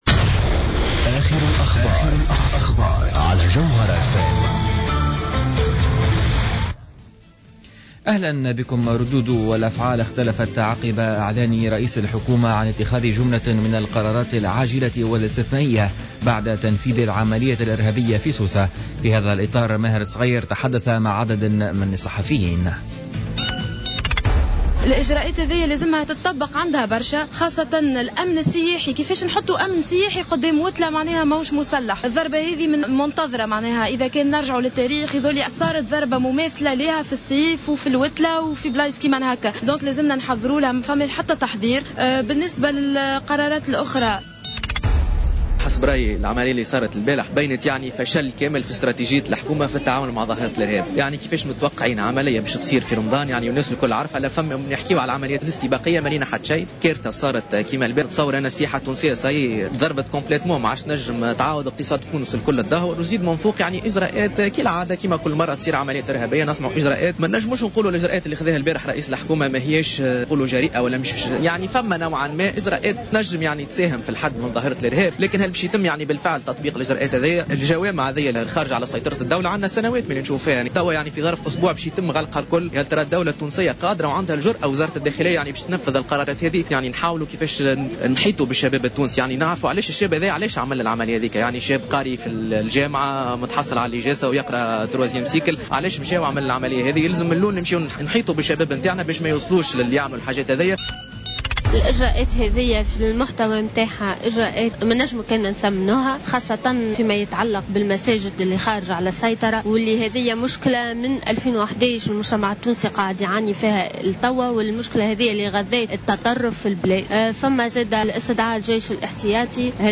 نشرة أخبار الخامسة مساء ليوم الأحد 28 جوان 2015